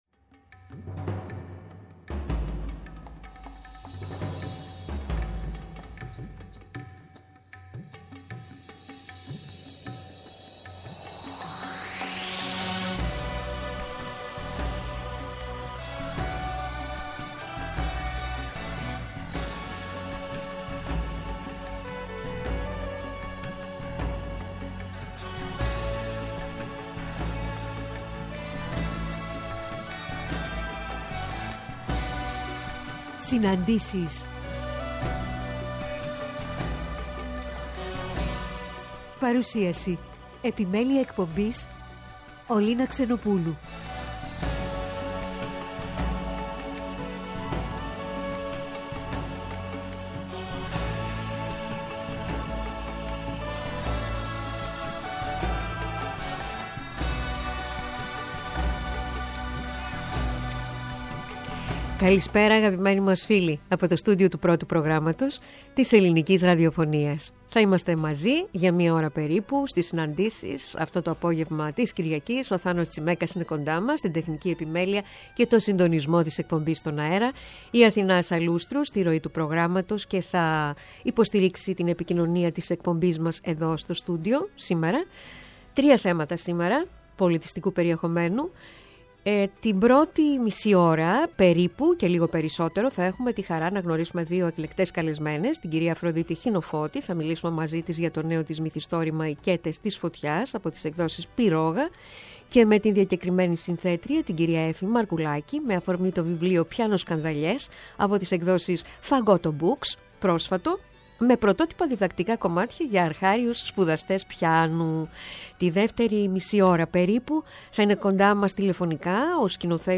Παρουσίαση – Ραδιοφωνική Παραγωγή